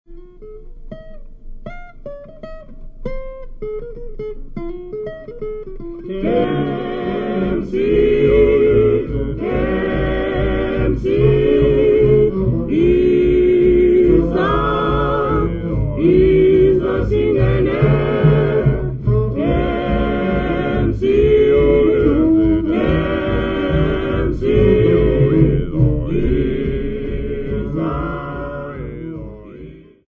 Cape inkspots of Grahamstown
Folk music--Africa
Field recordings
sound recording-musical
A topical song about a girl named Themb'sie accompanied by saxophone, double bass, drum and guitar.